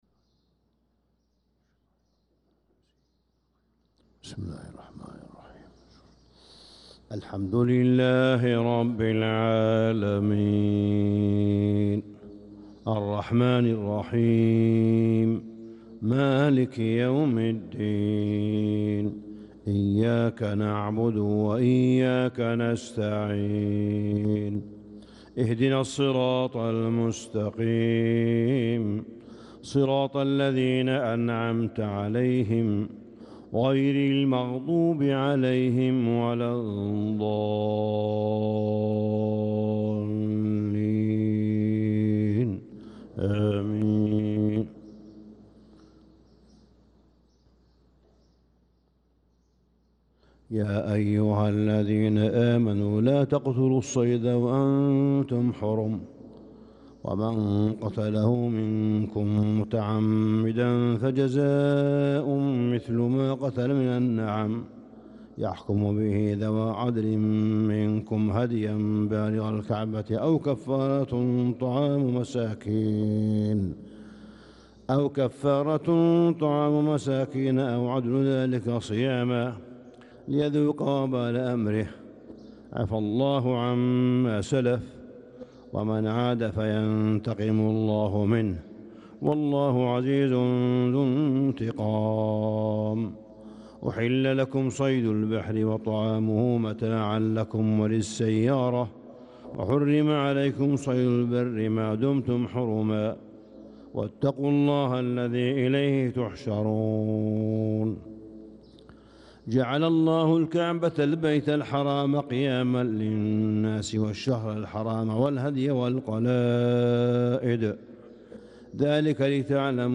صلاة الفجر للقارئ صالح بن حميد 1 ذو الحجة 1445 هـ
تِلَاوَات الْحَرَمَيْن .